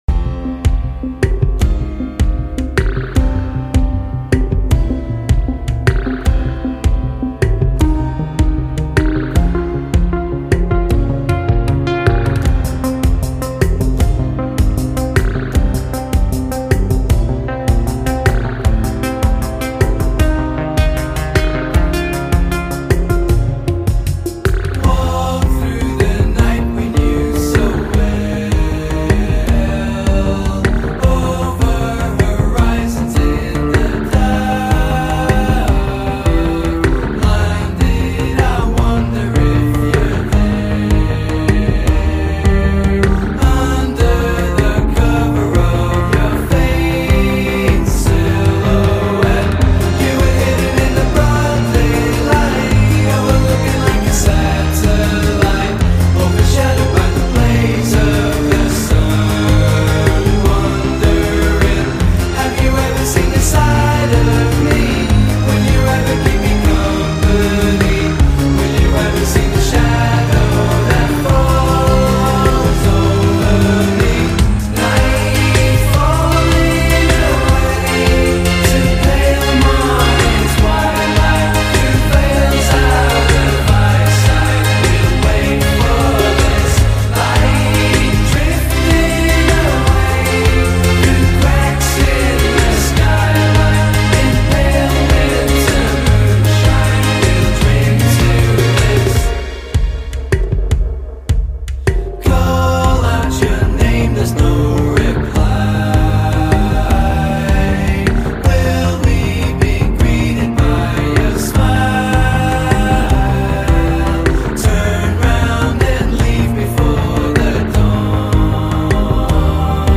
August 29, 2015 / / 80's reinvented
Categories80's reinvented synth pop